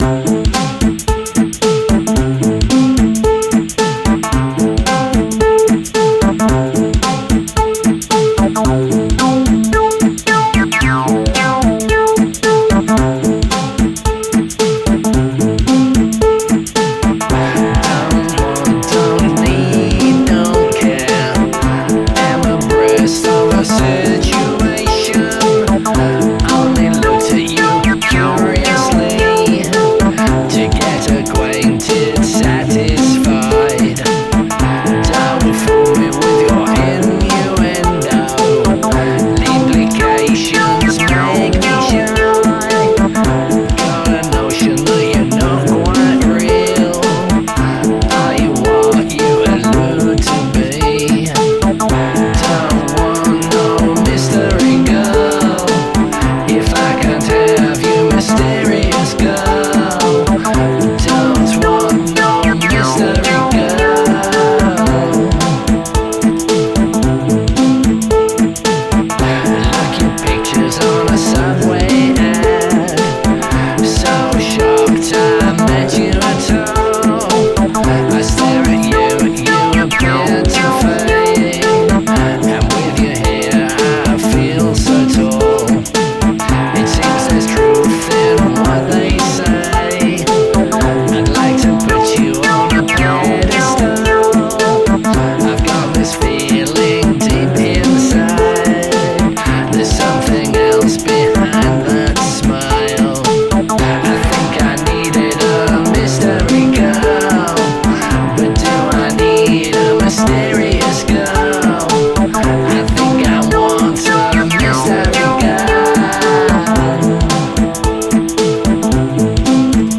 Mystery Girl - Drop/Open D version